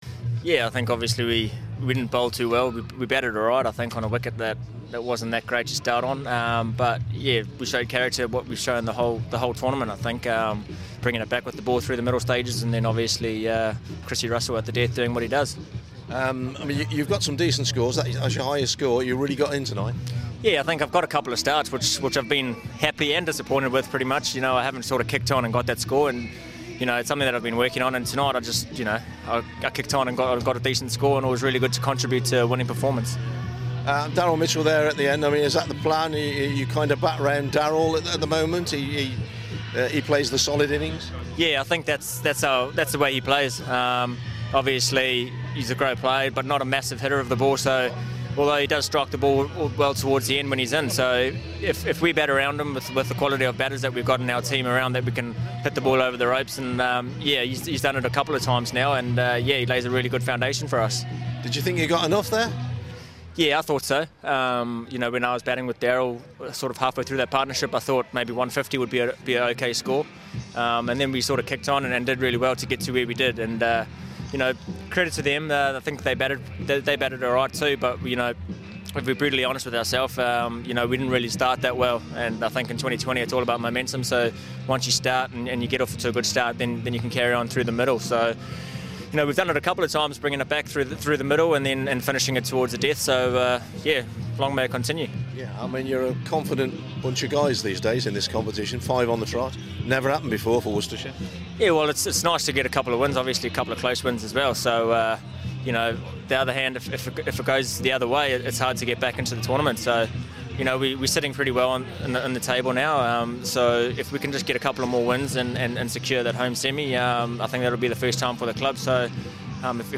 Worcestershire all rounder Colin Munro talks to BBC Hereford and Worcester aftre his team's thrilling 8 run win against Derbyshire in the T20.